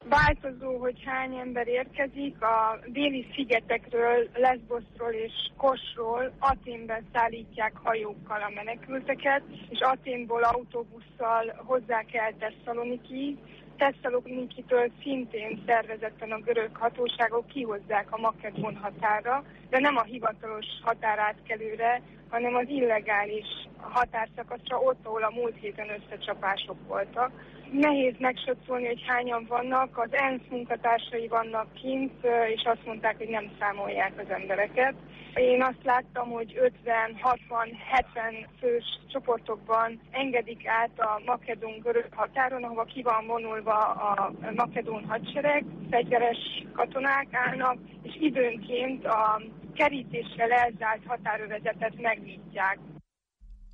A makedón-görög határról